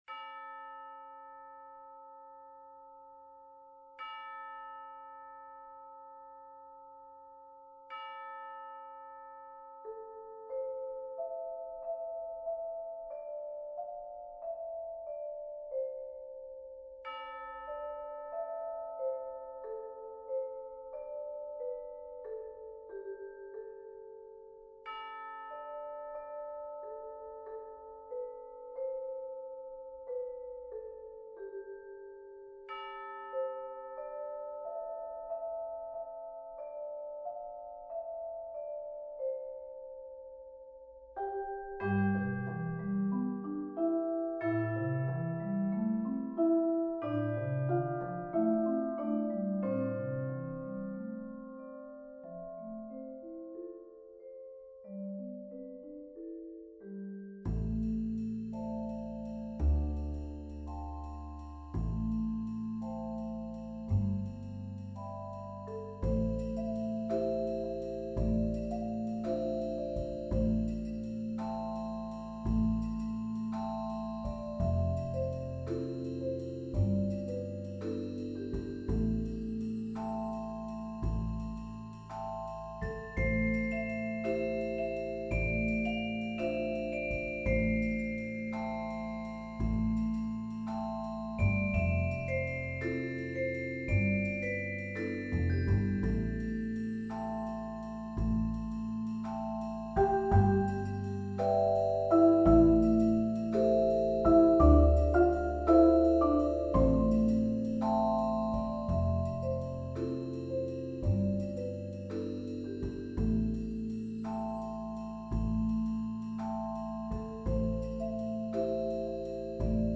Genre: Percussion Ensemble
# of Players: 7 + Bass
Chimes/Bells
Vibraphone 1
Vibraphone 2
Marimba 1 & 2 (playable on one 5-oct instrument)
Bass Guitar
Drum Set
Percussion (Triangle, Wind Chimes, Shaker)